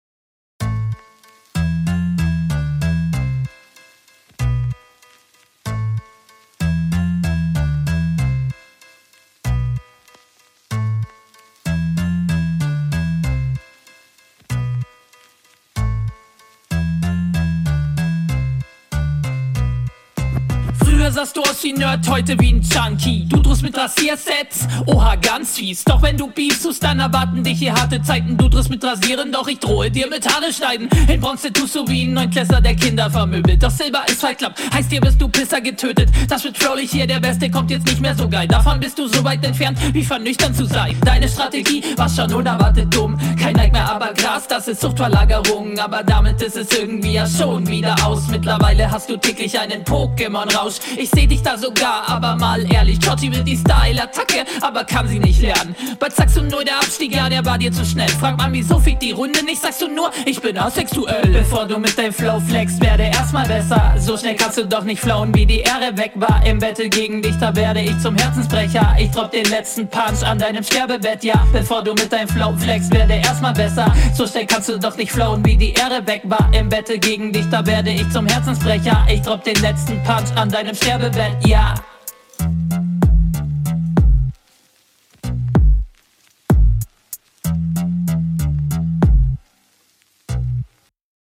gute hinrunde,stimme gefällt mir